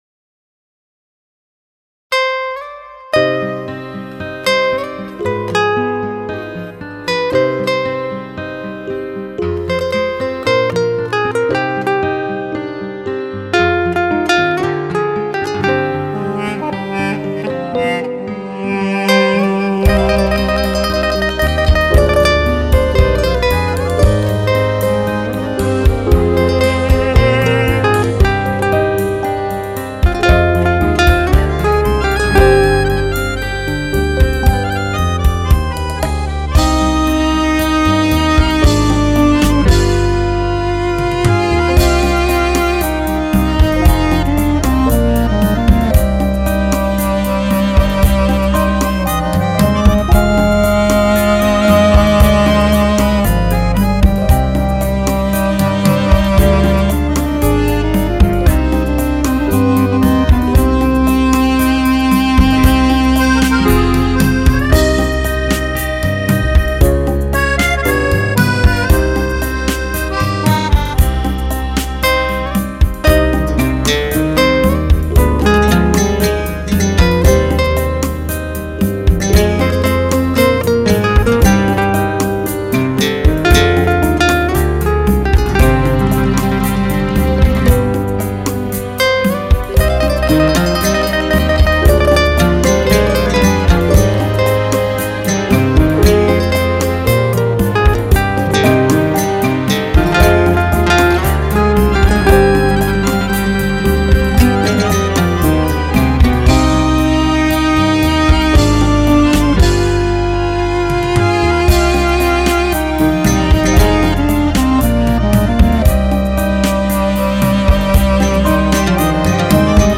آهنگساز و خواننده